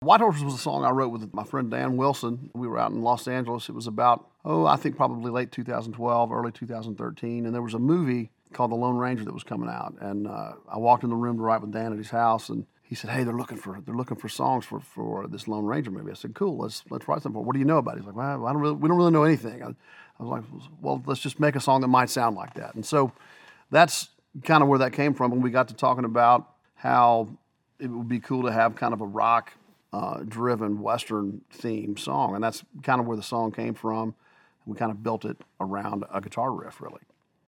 Audio / Chris Stapleton talks about writing “White Horse.”